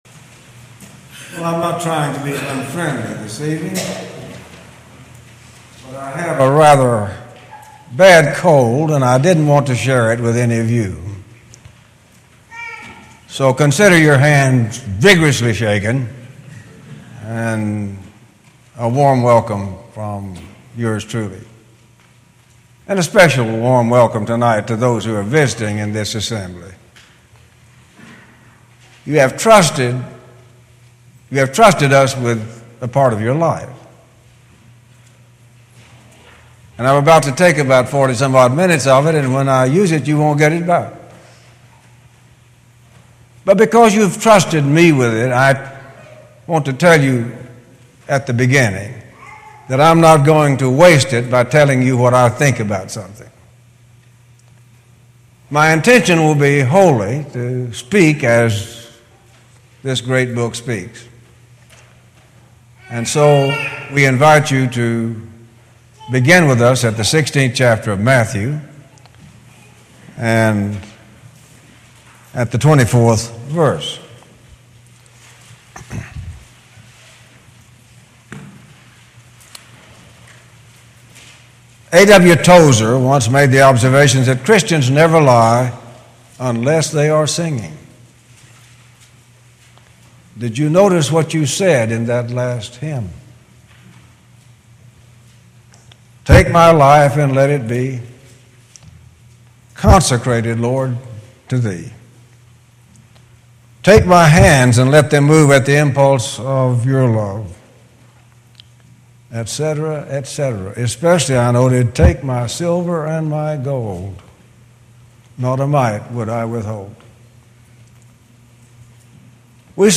Series: Gettysburg 2013 Gospel Meeting